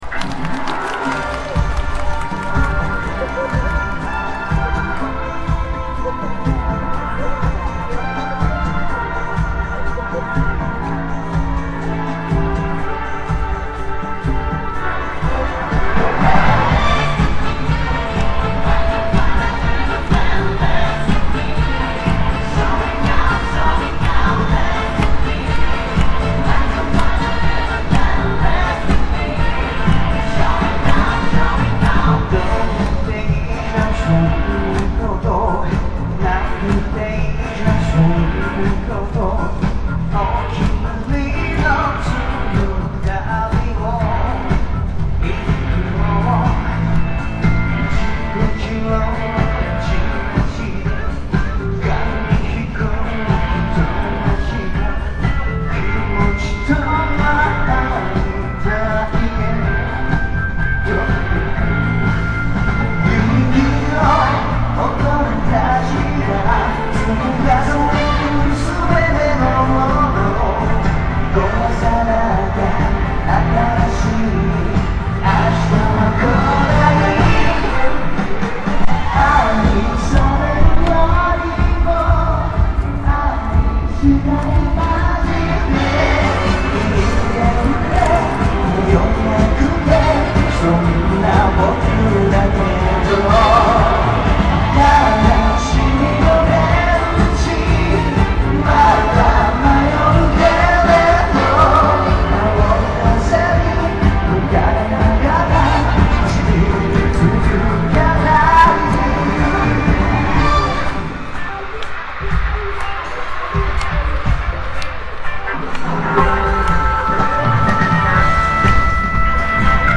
Conert repo